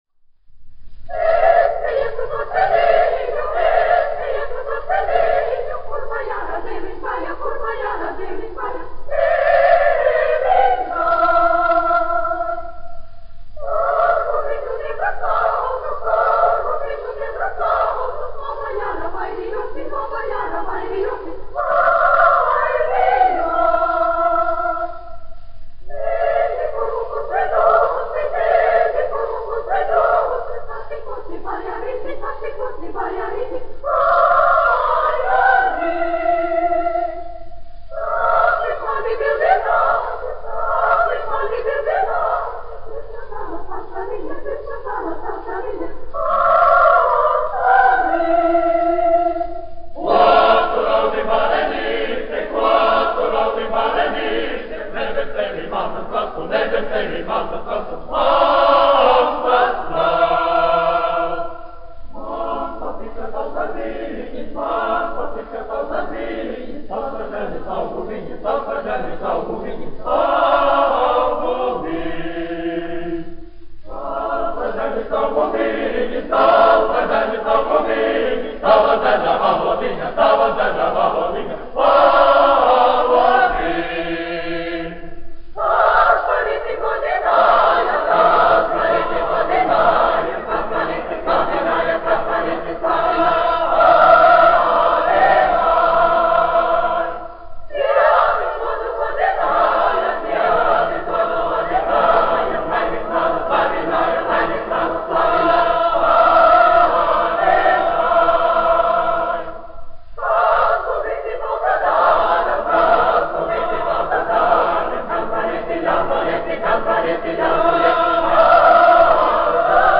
Emilis Melngailis, 1874-1954, aranžētājs
Kalniņš, Teodors, 1890-1962, diriģents
Latvijas Radio Teodora Kalniņa koris, izpildītājs
1 skpl. : analogs, 78 apgr/min, mono ; 25 cm
Kori (jauktie)
Latviešu tautasdziesmas